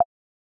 doodle_line.wav